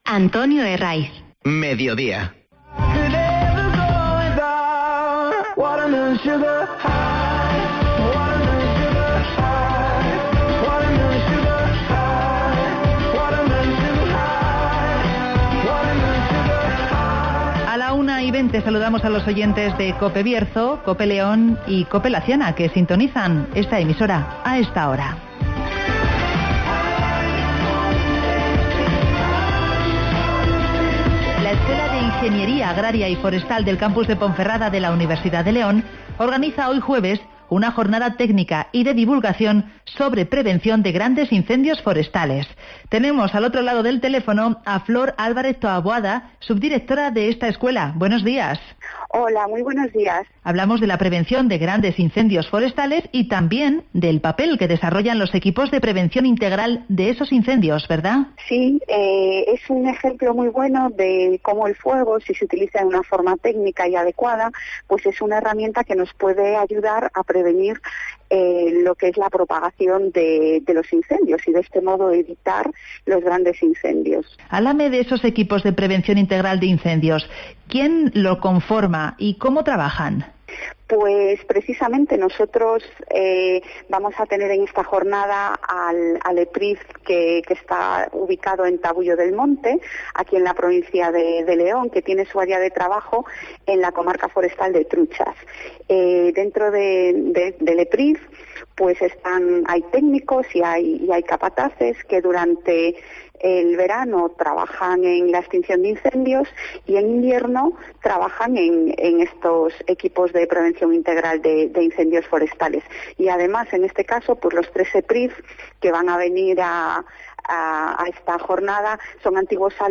El Campus de Ponferrada programa una jornada técnica sobre prevención de incendios (Entrevista